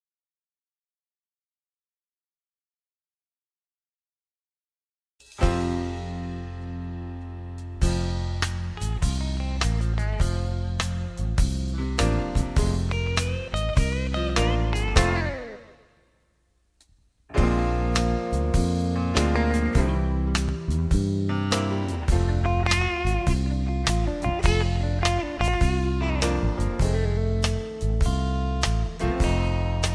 country rock, country music